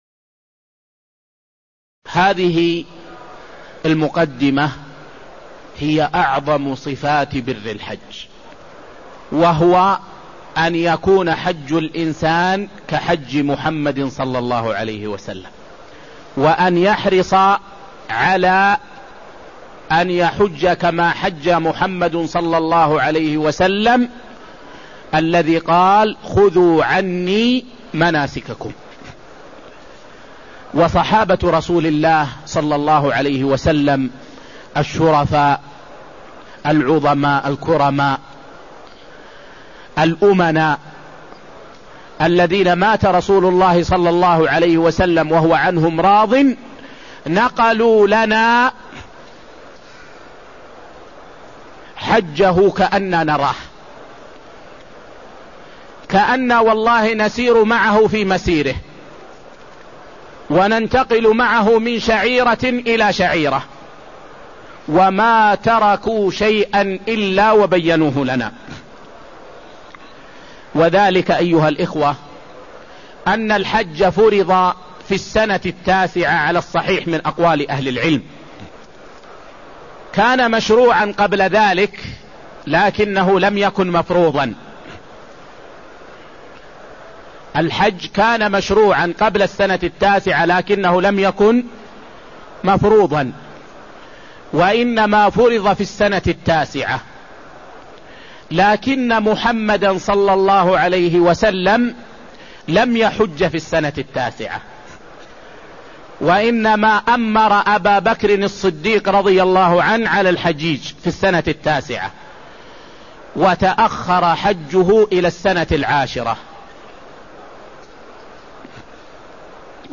تاريخ النشر ١٤ ذو القعدة ١٤٢٦ هـ المكان: المسجد النبوي الشيخ